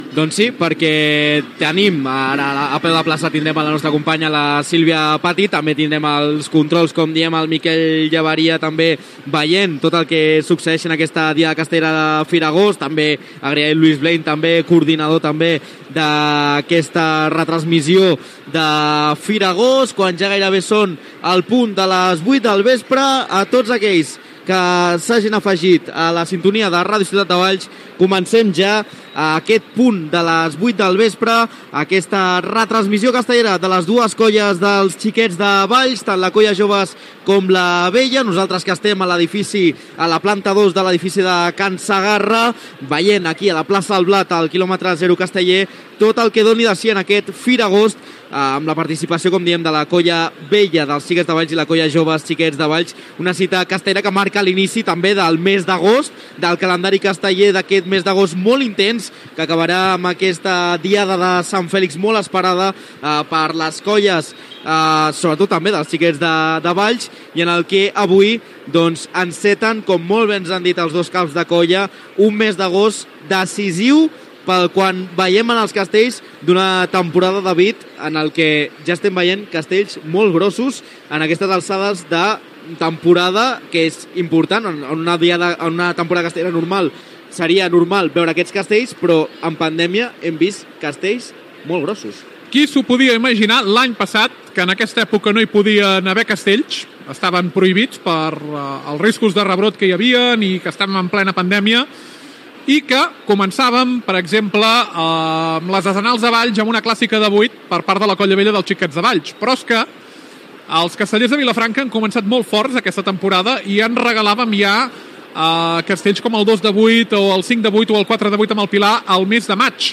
Inici de la transmissió de la Diada Castellera de la Firagost de 2022 a Valls. Equip, hora, descripció de l'ambient, resum de la temporada castellera, castells previstos per a la diada.
Informatiu